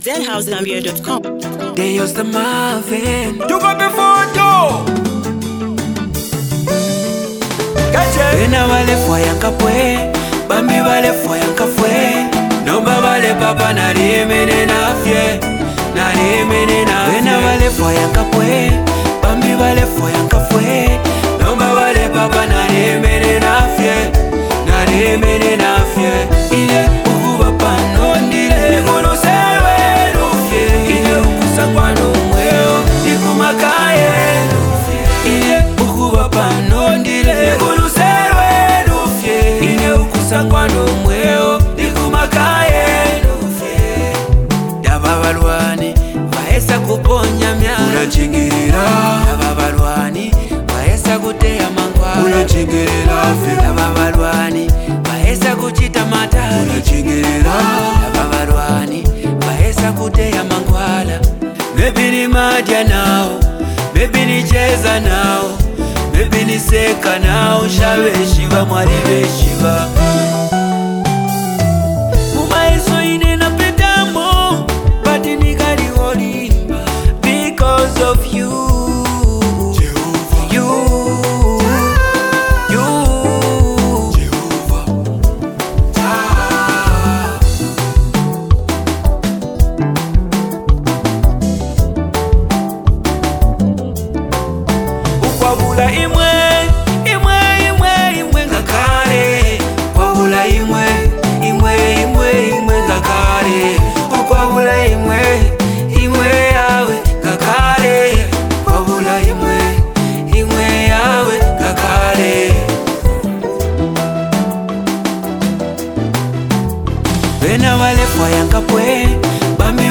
soul-stirring new single